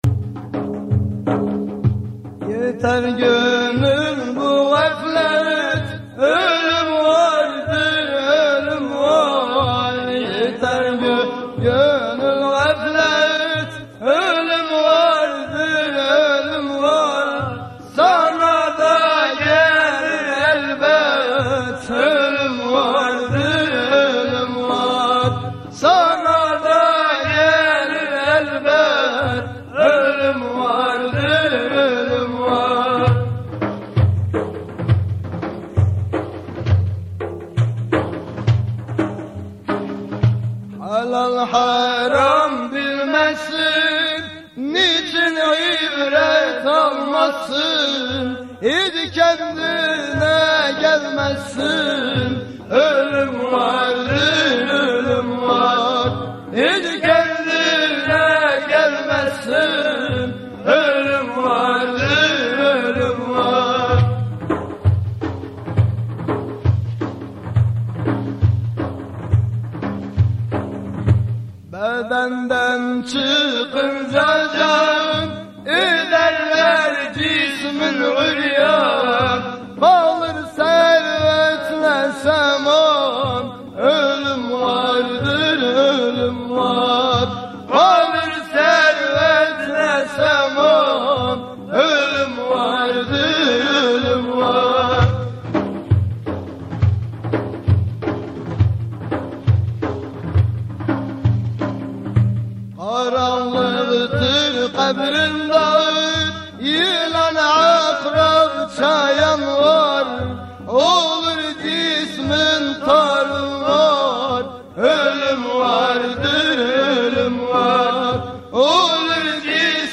Etiketler: şanlıurfa, Tasavvuf